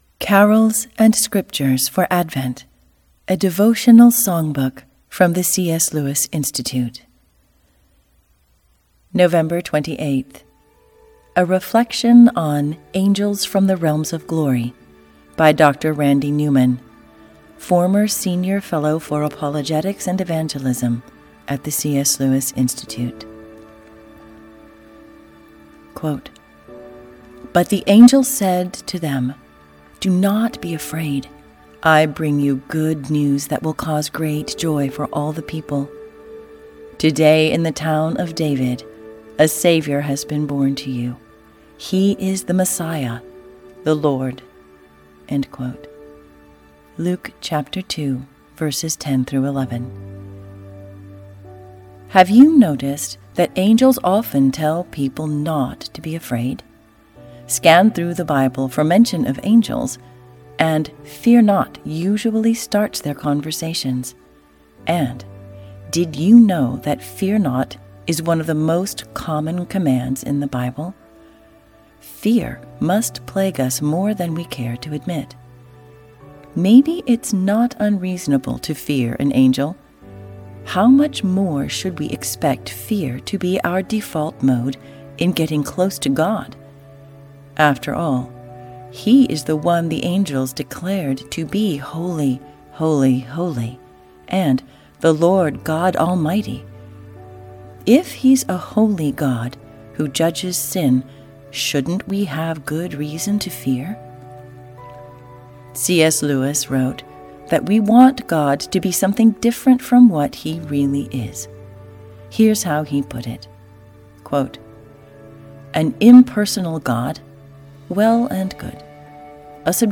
Explore 30 beloved Christmas carols accompanied by original piano recordings. Each carol is paired with a passage of Scripture, a short reflection, and master artwork for the Advent Season.